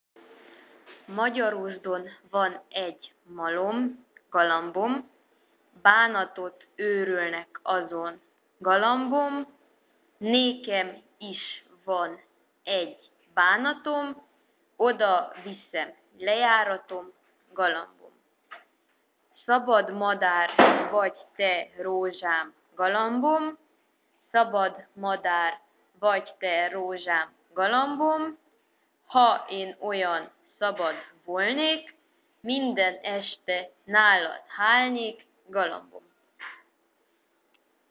誦讀穆也納柏女子圓環歌詞(2)